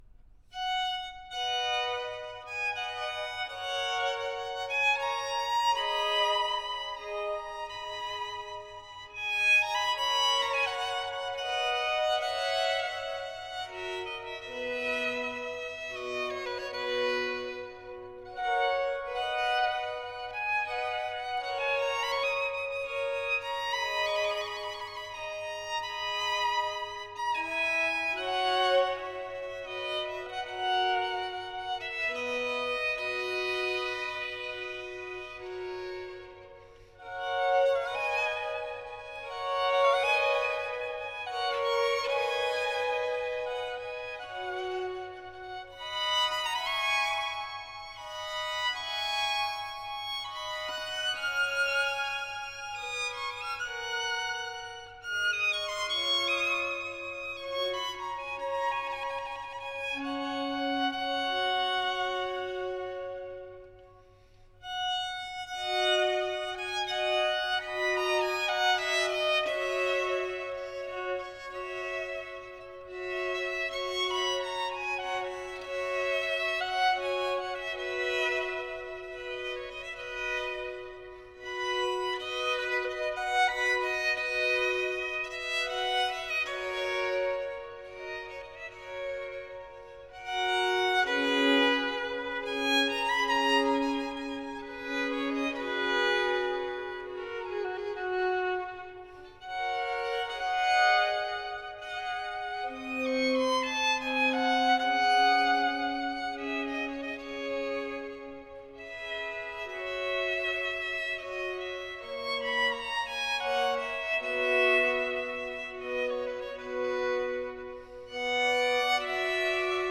Violin Concerto In E Major - d.52 - ADAGIO